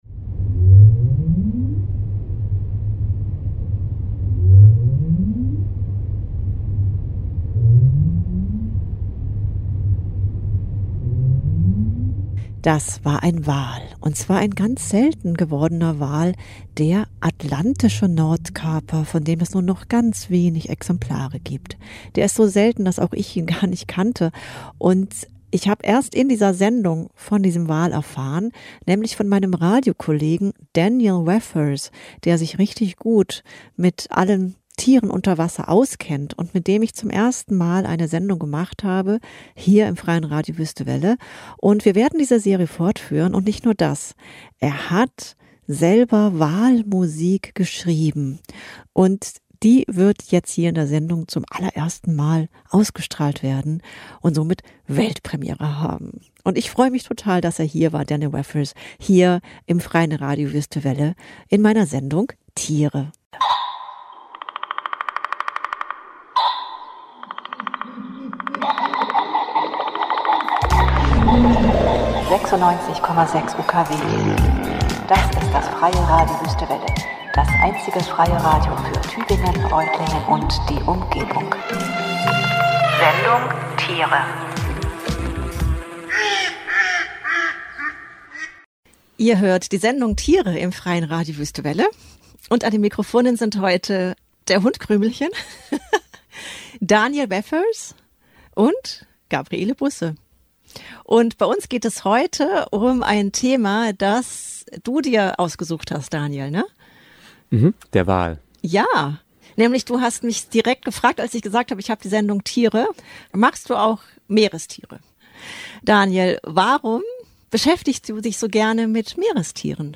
So haben wir zusammen spontan eine Sendung improvisiert, die sich mit einem Tier beschäftigt, das es schon bald nicht mehr geben wird.